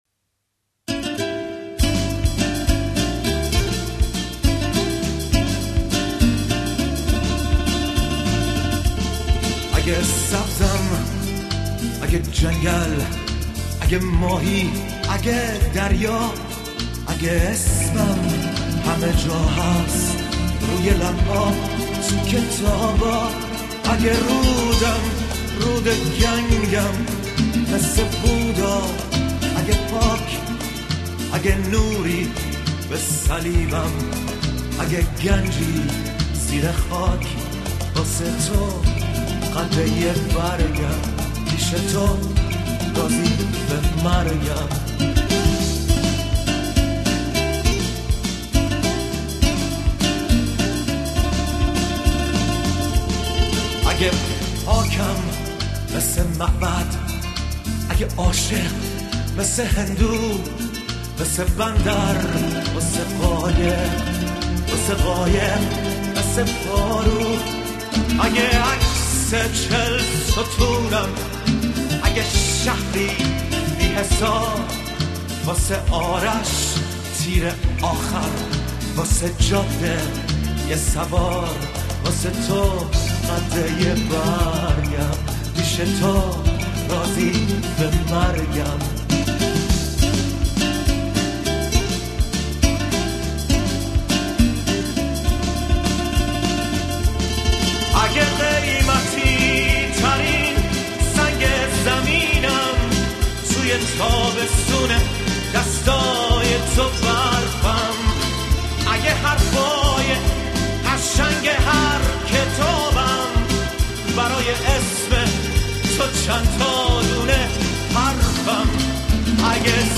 اجرا در واشنگتن / ۱۹۹۳ میلادی